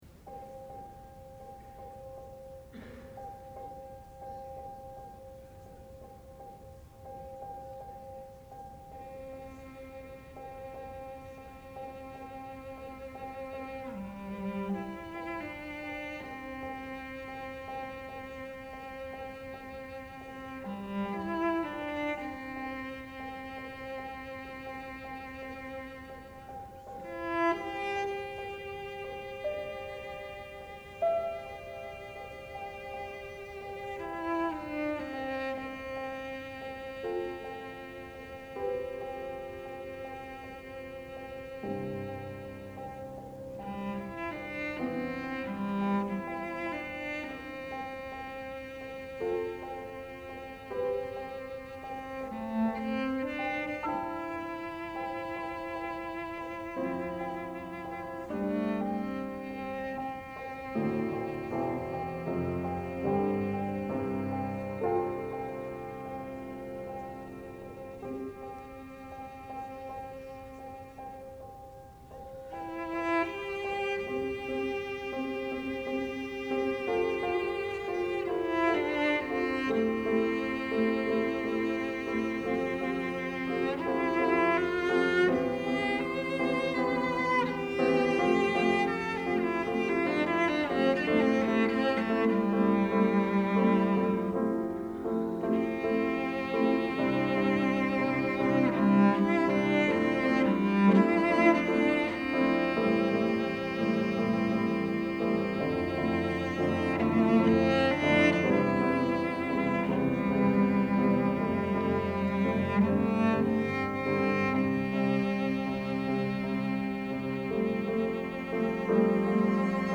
for Piano Trio (or Clarinet, Cello, and Piano) (1990)